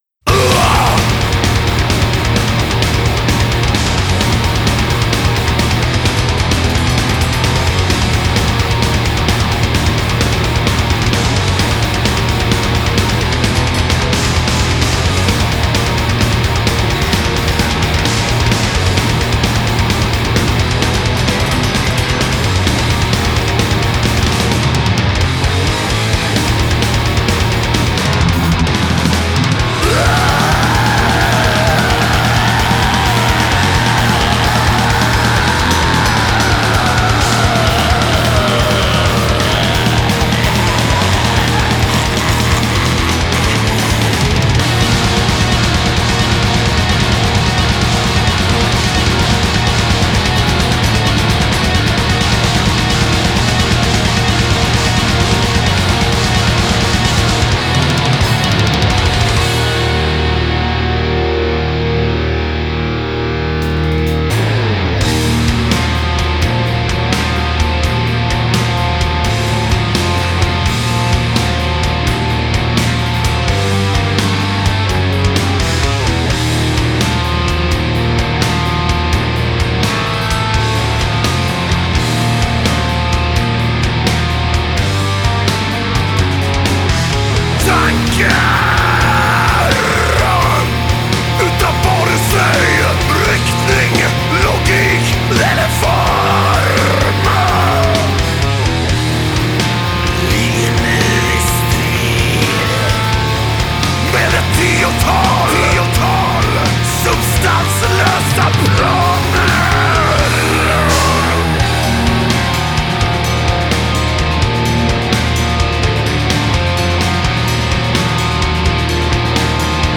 Depressive suicidal black metal Album